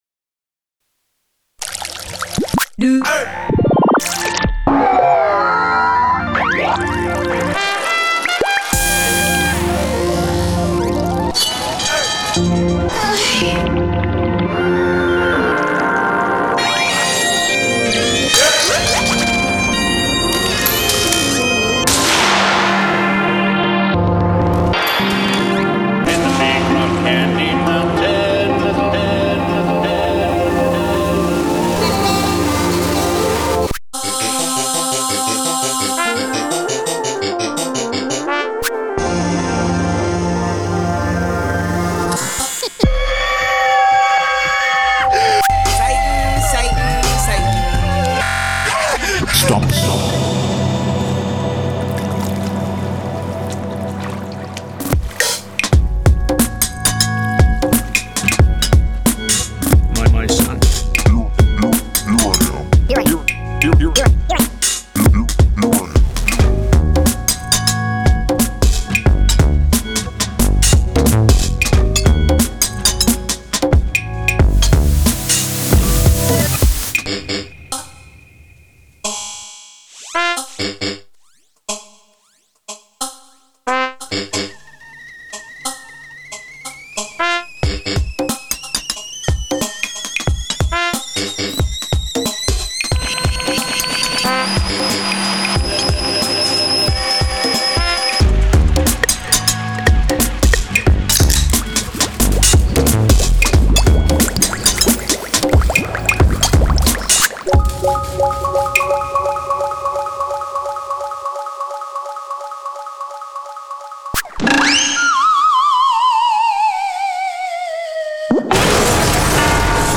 Just a simple sequence, but man, those rytm drums just have such an impact.